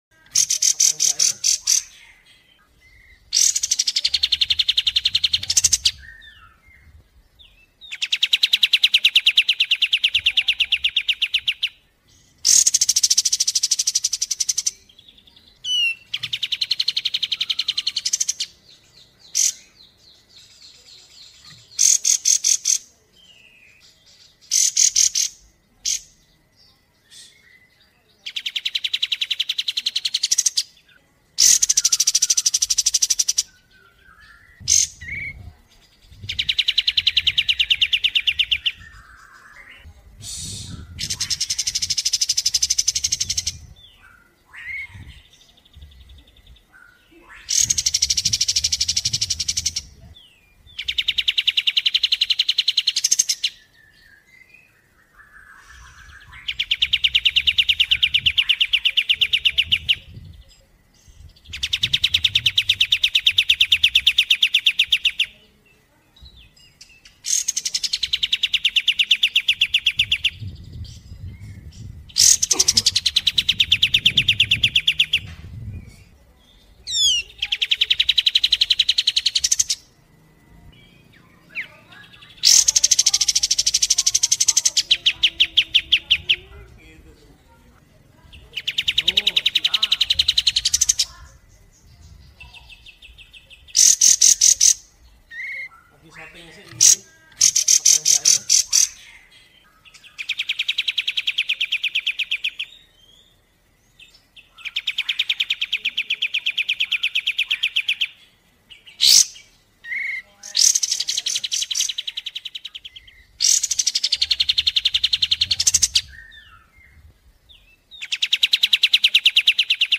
Download suara Kapas Tembak gacor jeda 2 menit MP3 dengan kualitas suara jernih!
Suara burung Kapas Tembak JERNIH JEDA 2 menit
Tag: suara burung kapas tembak suara burung kecil suara masteran burung
Suara tembakan rapat dan konsisten ini akan membantu burung Anda lebih cepat gacor.
suara-burung-kapas-tembak-jernih-jeda-2-menit-id-www_tiengdong_com.mp3